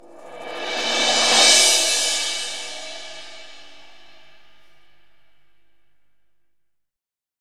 Drums/CYM_NOW! Cymbals